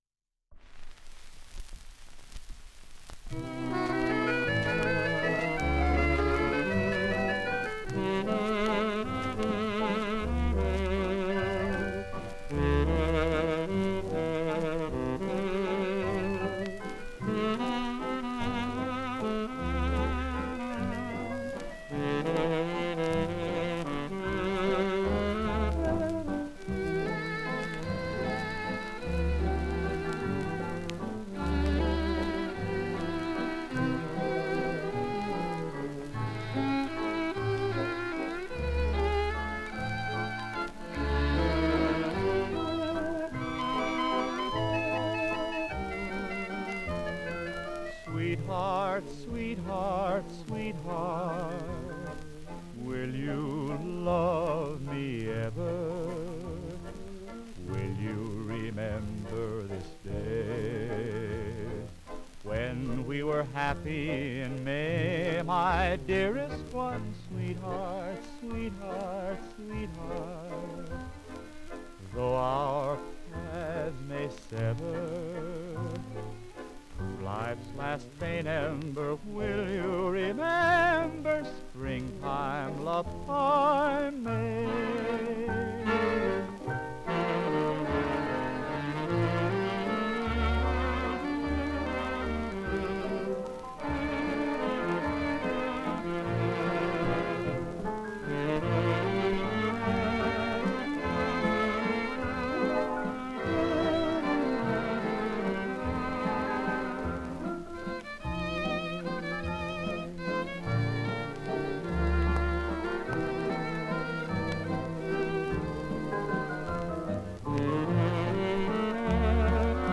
Genre: Medley.